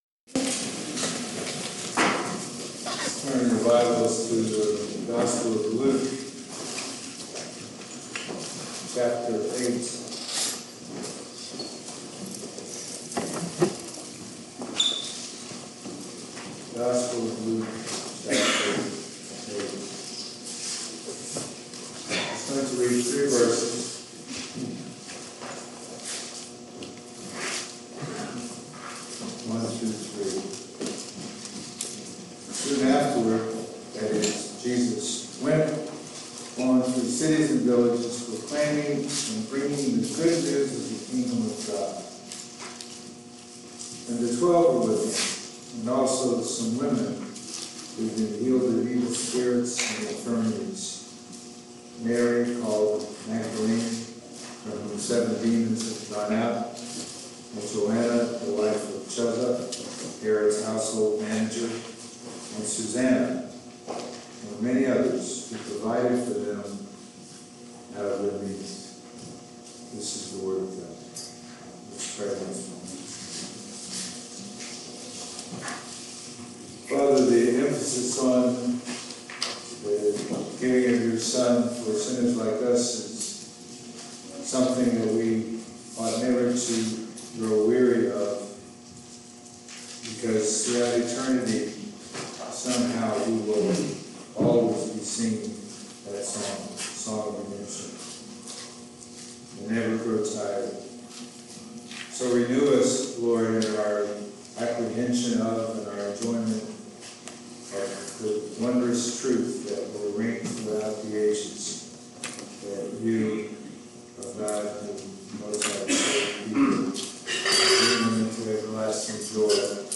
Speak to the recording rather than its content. Luke 8:1-3 Service Type: Sunday Morning « Forgiven By Jesus He Who Has Ears to Hear